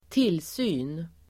Uttal: [²t'il:sy:n]